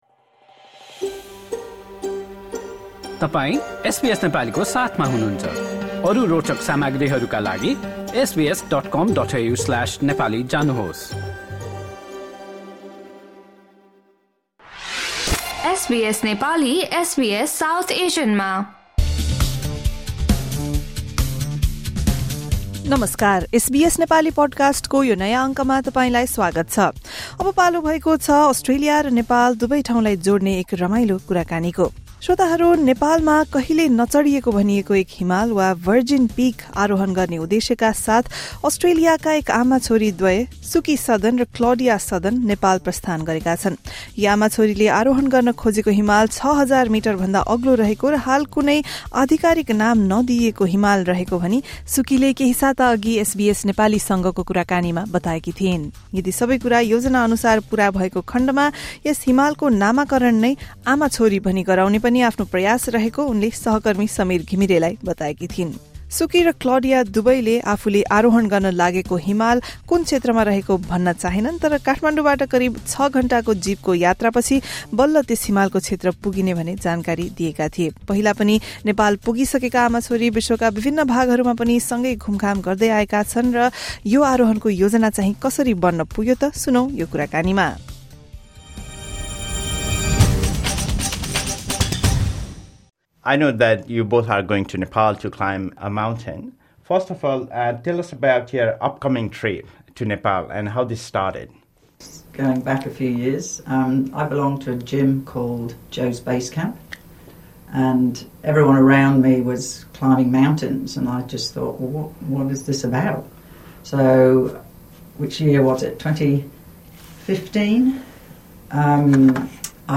The Australian mother-daughter duo spoke to SBS Nepali before their trip to the Himalayas.